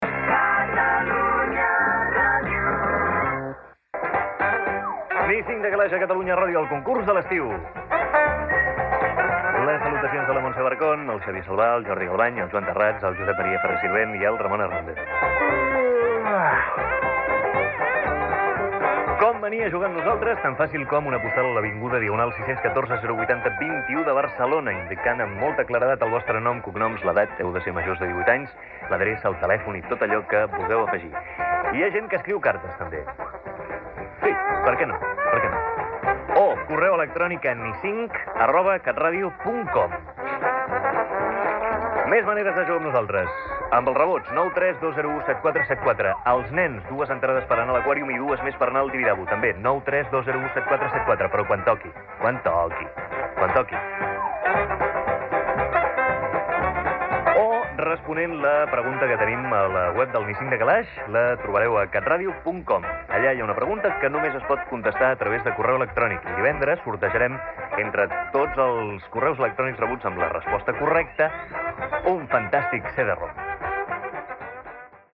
Indicatius de l'emissora, careta del programa i presentació inicial amb l'adreça de contacte.
Entreteniment